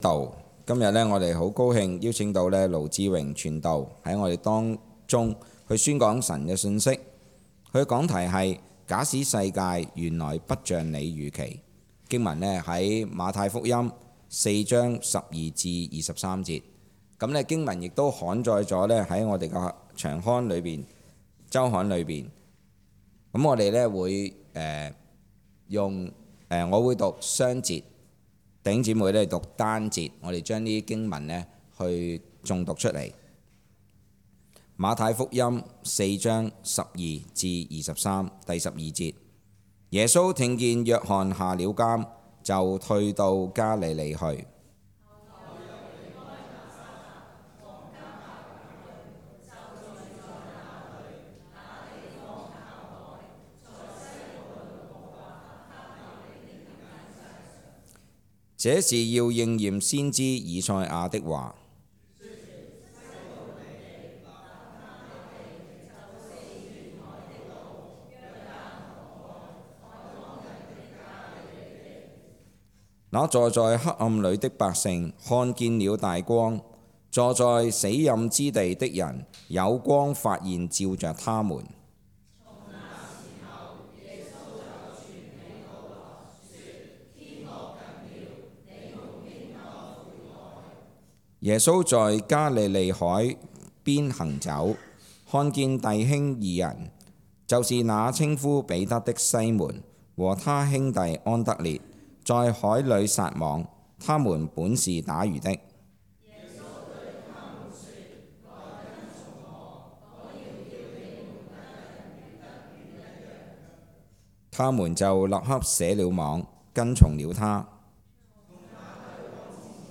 主日崇拜